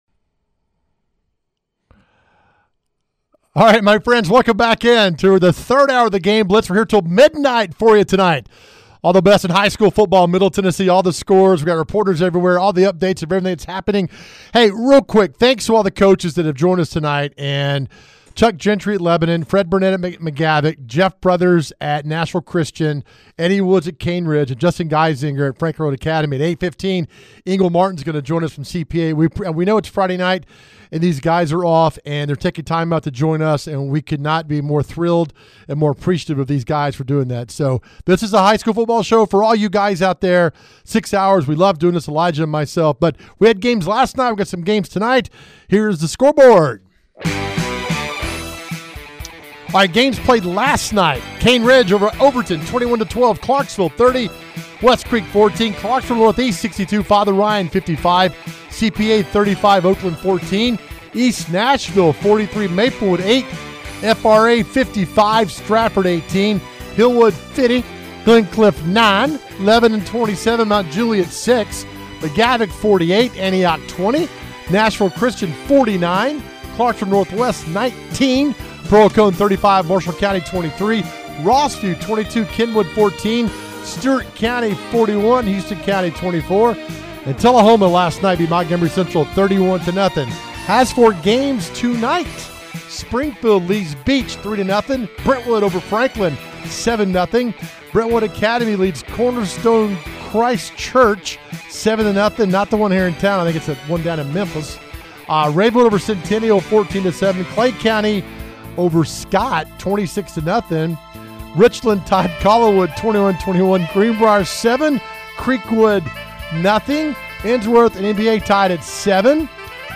They bring you six hours of everything in the world of Middle TN High School Football. We have interviews with coaches and reporters live at the hottest games!